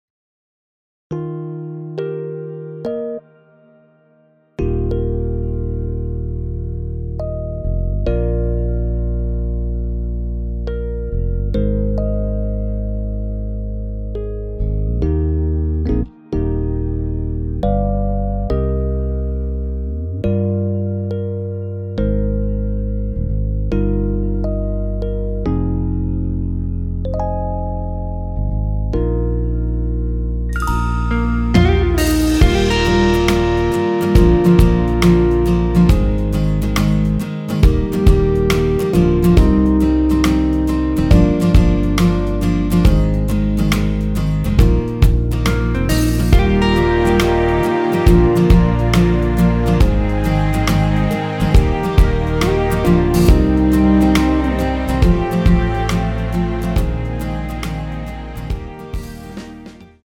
전주 없이 시작 하는 곡이라 전주 1마디 만들어 놓았습니다.(미리듣기 참조)
원키에서(-7)내린 MR입니다.
Eb
앞부분30초, 뒷부분30초씩 편집해서 올려 드리고 있습니다.
중간에 음이 끈어지고 다시 나오는 이유는